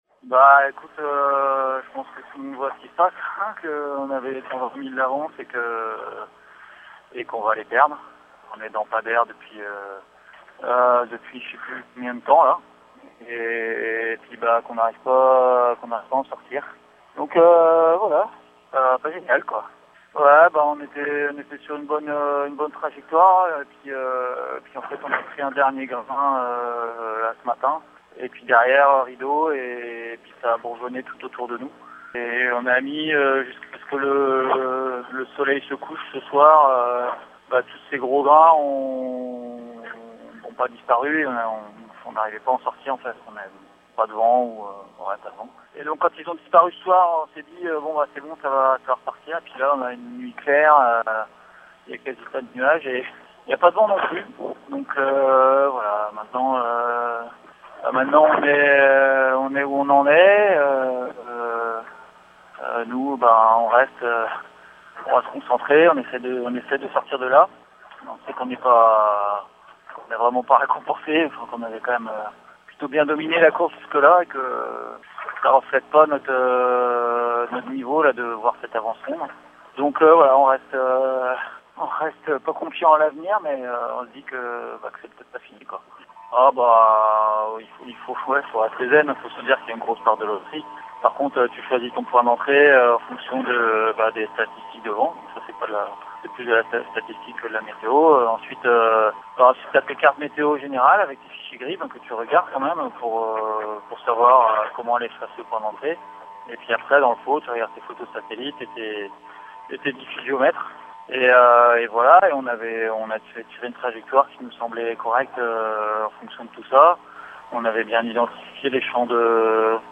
Les mots des skippers
Jérémie Beyou, skipper de Charal (IMOCA)